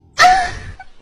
Ughh Sound Effect Free Download
Ughh